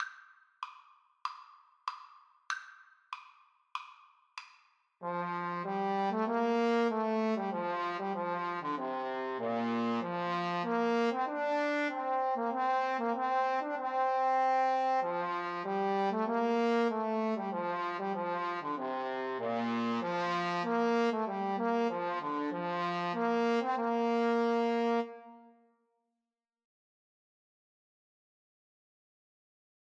Free Sheet music for Trombone Duet
Trombone 1Trombone 2
Maestoso = c. 96
Bb major (Sounding Pitch) (View more Bb major Music for Trombone Duet )
4/4 (View more 4/4 Music)
Classical (View more Classical Trombone Duet Music)